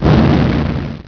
explo.wav